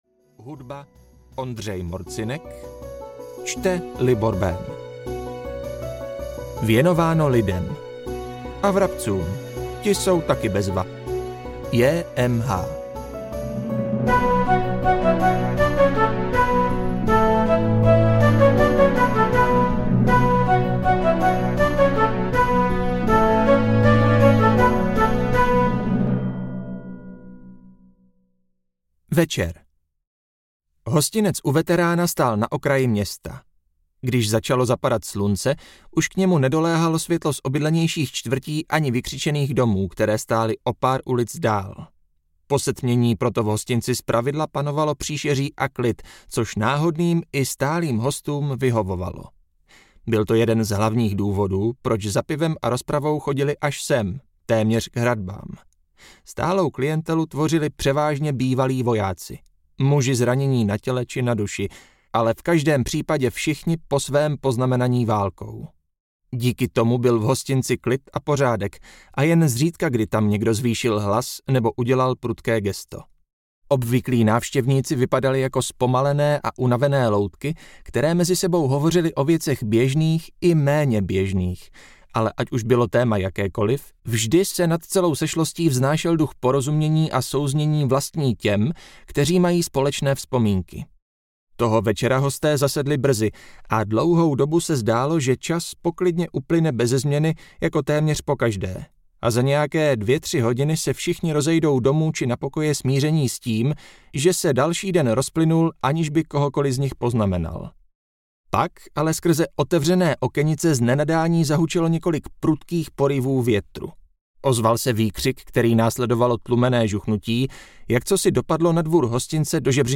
Valard & vejce na draka audiokniha
Ukázka z knihy